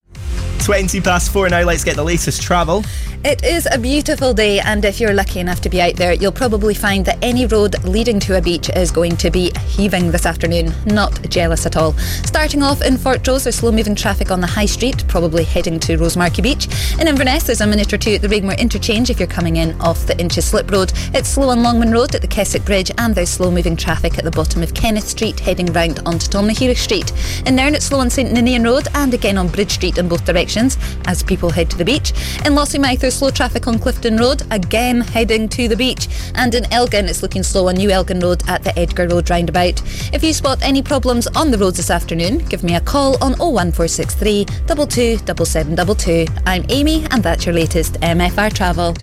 MFR travel bulletin at 1620 on Tuesday 19/07/16